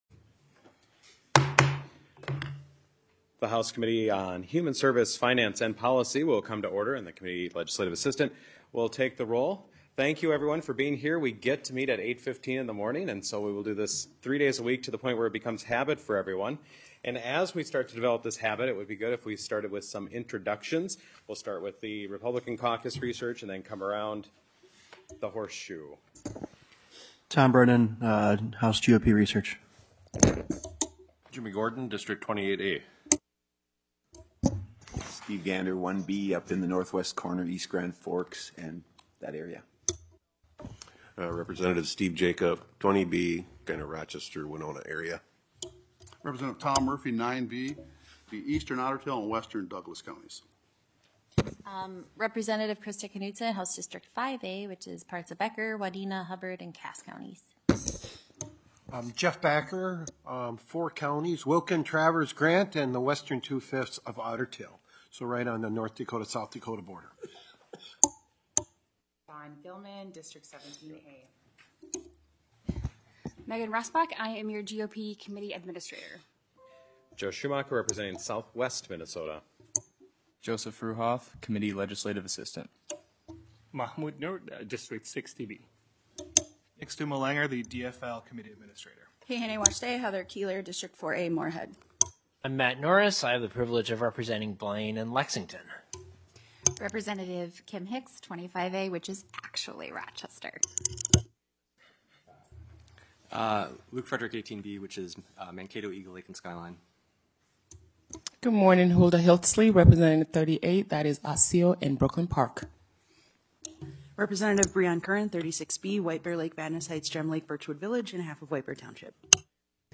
Human Services Finance and Policy FIRST MEETING - Minnesota House of Representatives